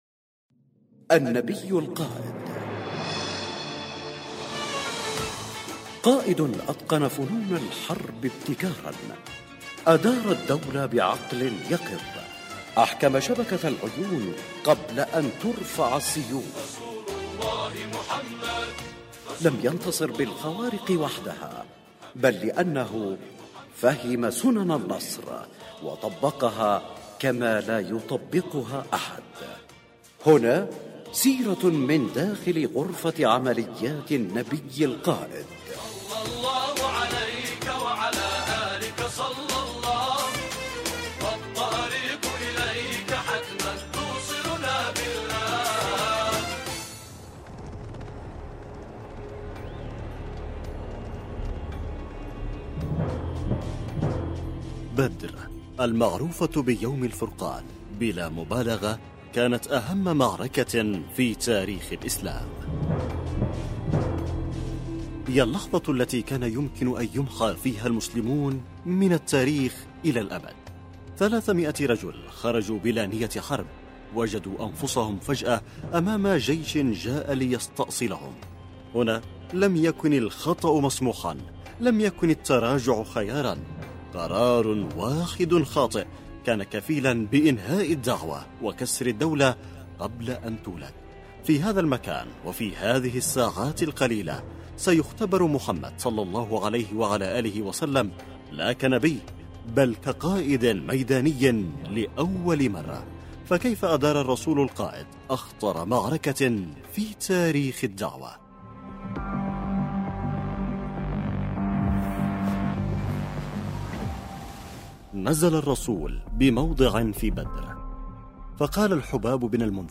النبي القائد، برنامج إذاعي يقدم الجوانب العسكرية والأمنية في السيرة النبوية للنبي الاكرم صلى الله عليه واله مع الاعتماد بشكل كلي على ما ذكره السيد القائد يحفظه الله في محاضراته خلال رمضان وخلال المولد النبوي الشريف.